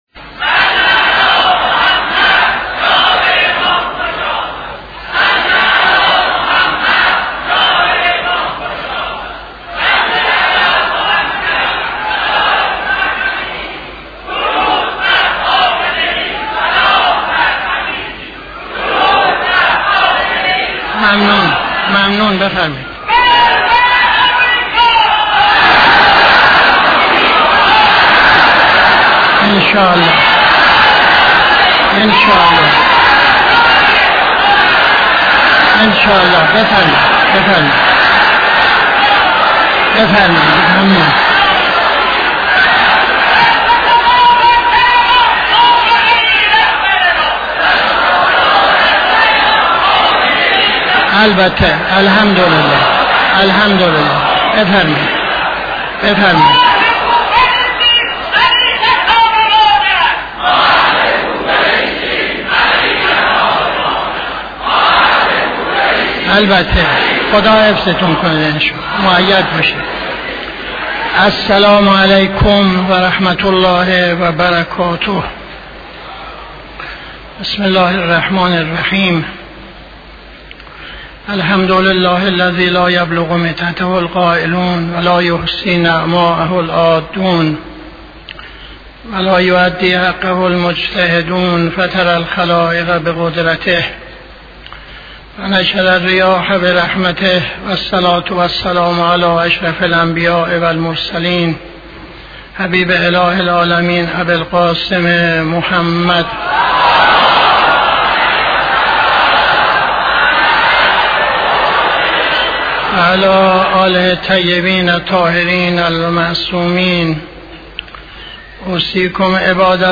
خطبه اول نماز جمعه 20-07-80